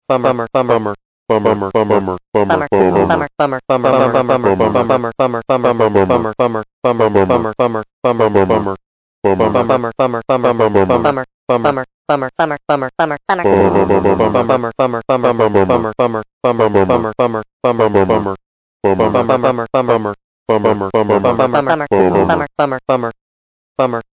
This was one of my first experiments with CoolEdit2000, the trial version software I first used to make songs.
I'm not sure how I managed to get it to say "bummer" so clearly on each note, because now all I can get is this croak sound when I try to set a clip to music.